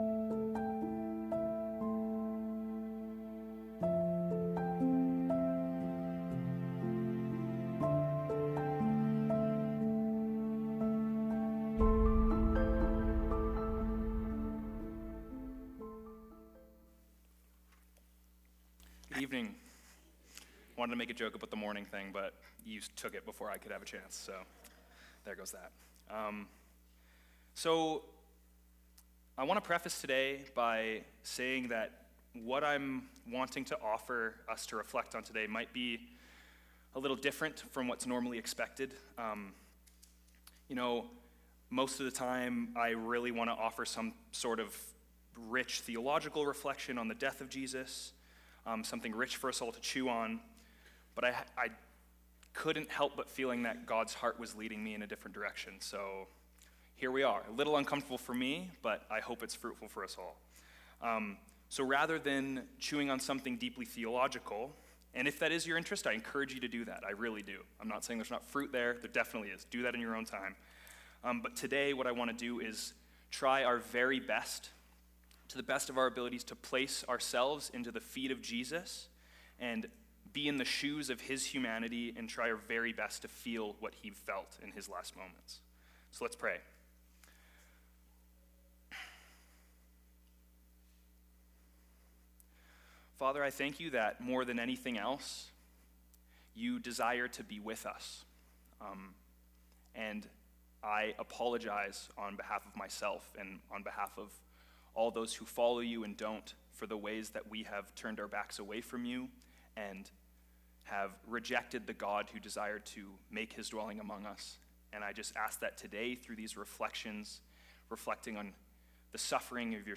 Good-Friday-Service-2026.m4a